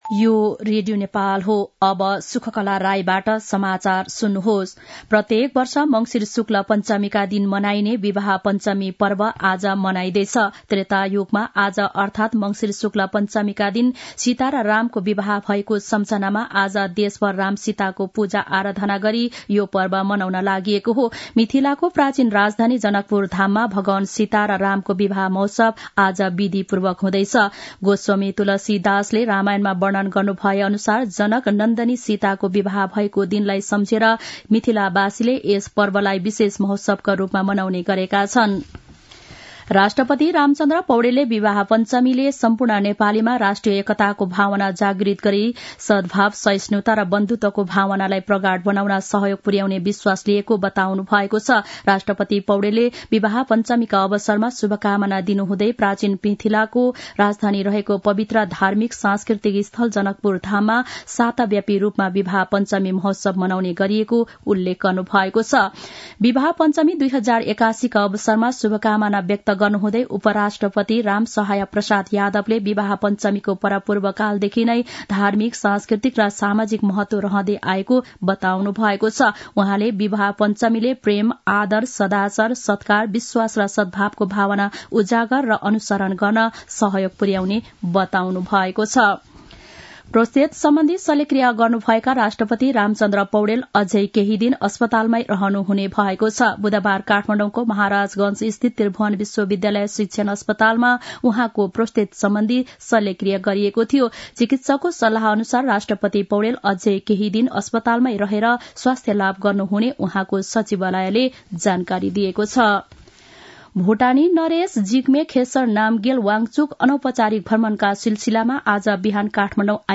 मध्यान्ह १२ बजेको नेपाली समाचार : २२ मंसिर , २०८१
12-am-nepali-news-1-4.mp3